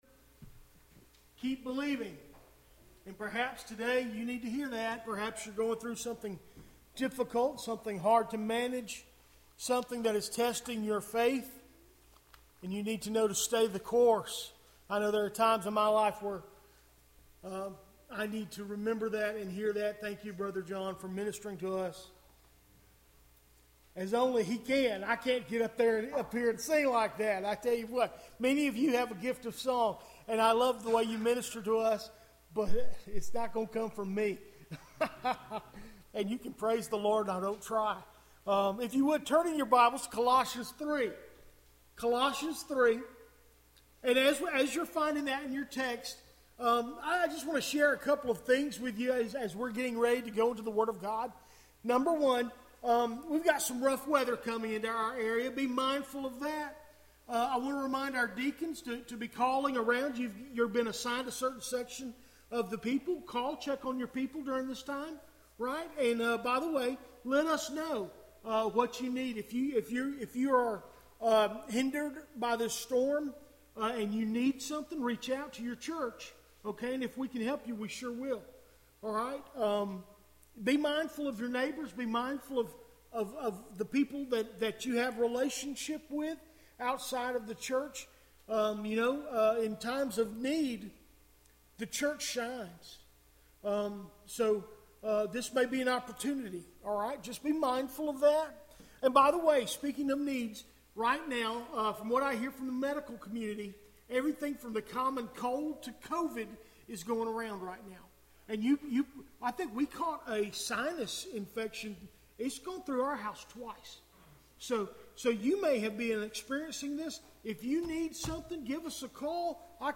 Recent Sermons - Doctor's Creek Baptist Church